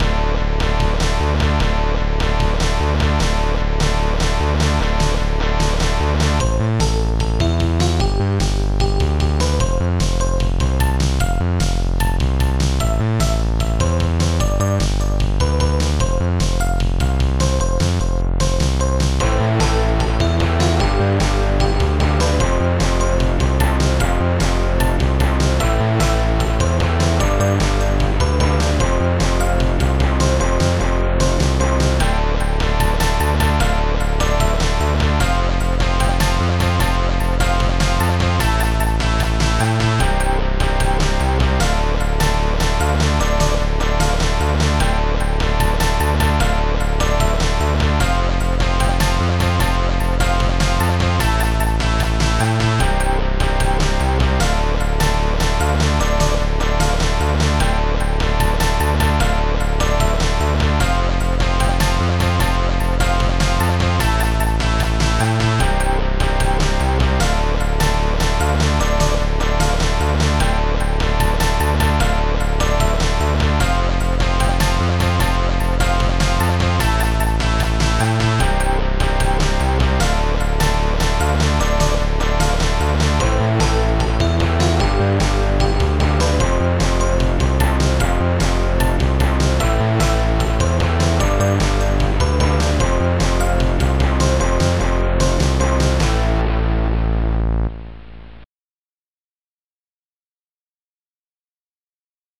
Protracker Module
Instruments future brass distguit distguit bassdrum7 snaredrum8